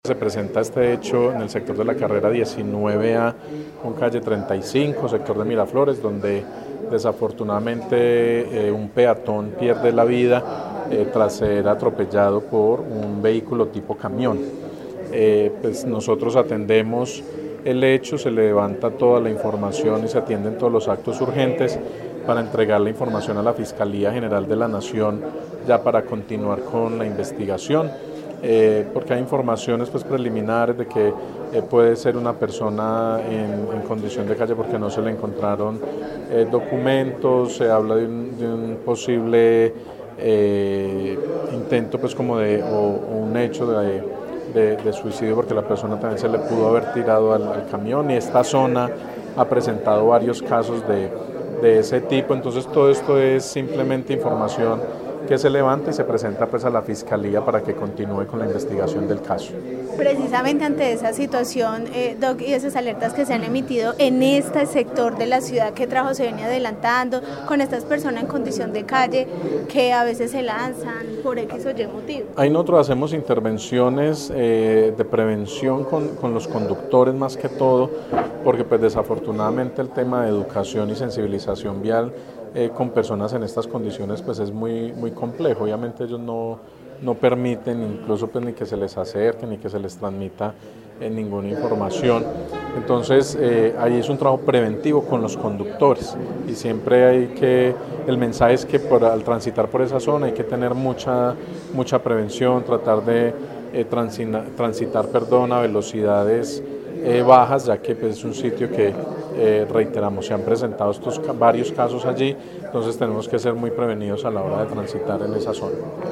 Secretario de Tránsito de Armenia